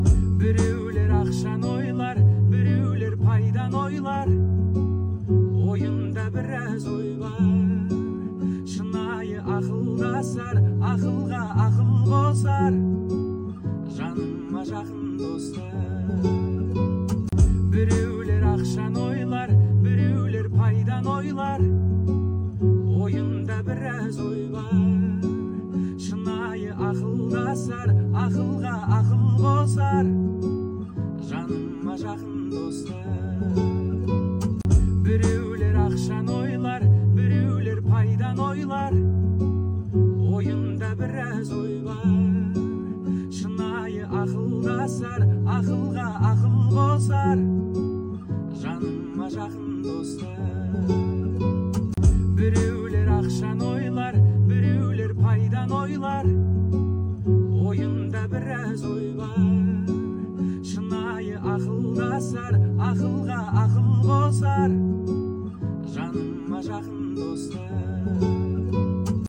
Жанр: Новинки русской музыки